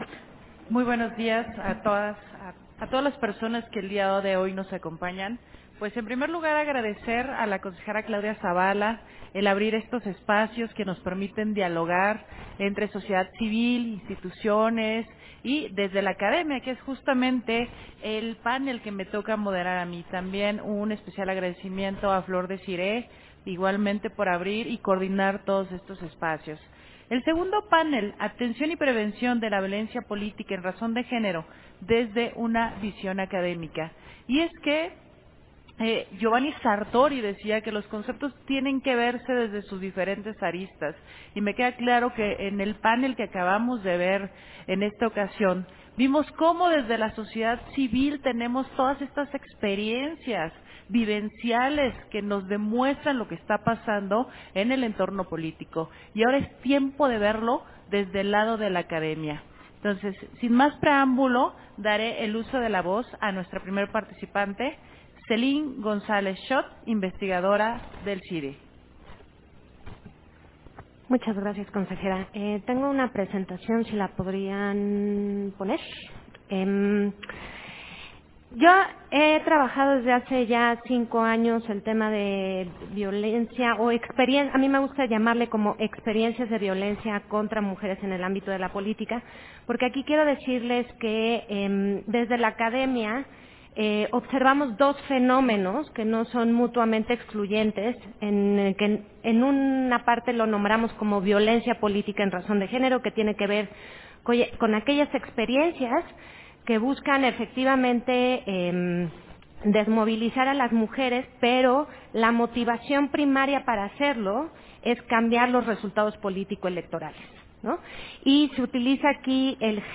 Segundo panel: Atención y prevención de la VPMRG desde una visión académica
Día Internacional de la Eliminación de las Violencias Contra las Mujeres